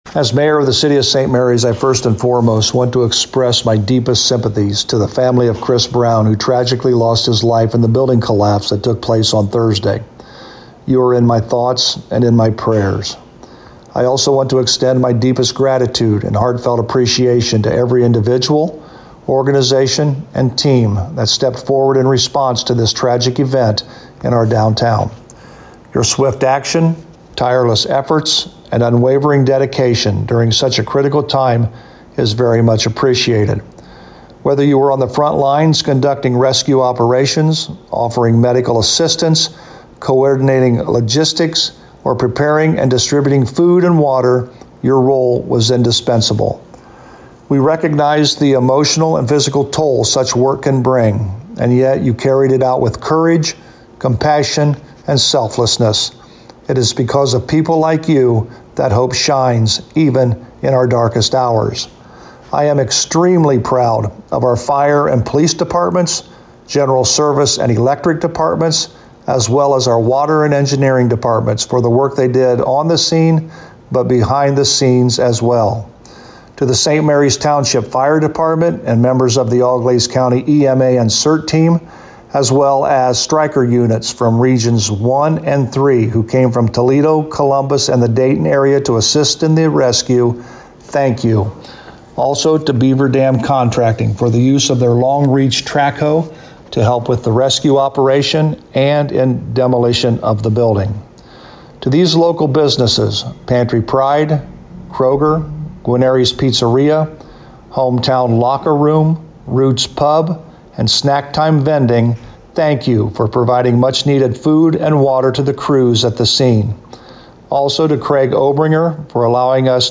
Local News
St Marys Mayor Joe Hurlburt thanks the community and first responders in response to Thursday's tragedy: